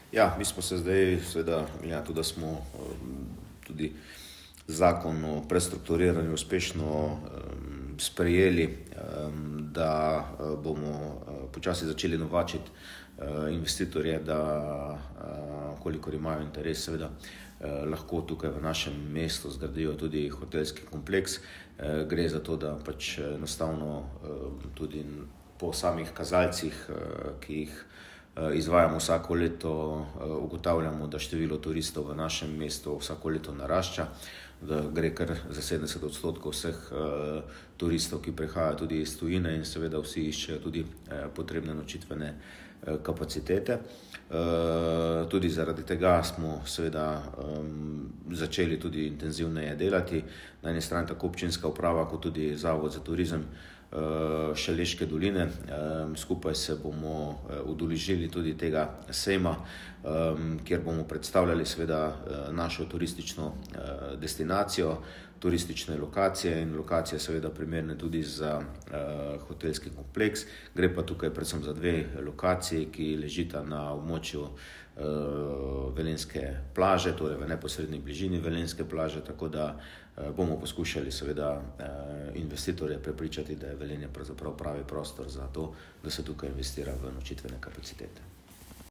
izjavo župana Mestne občine Veleje Petra Dermola.